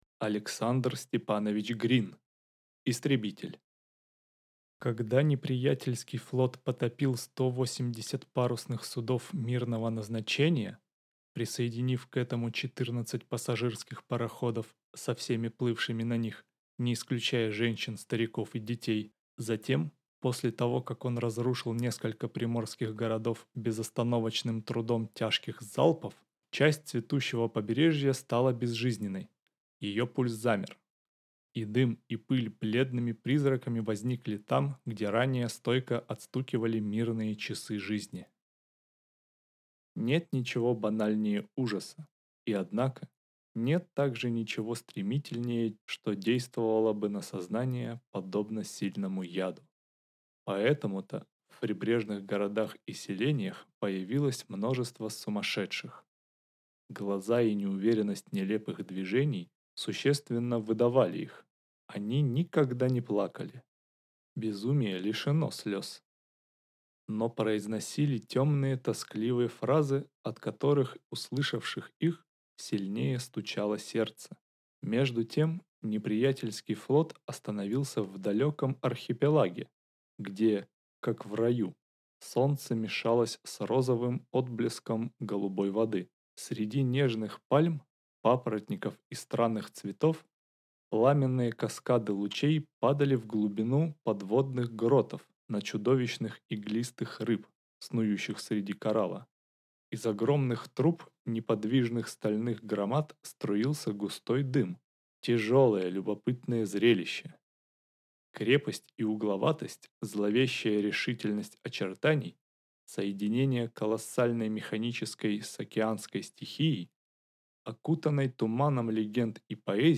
Аудиокнига Истребитель | Библиотека аудиокниг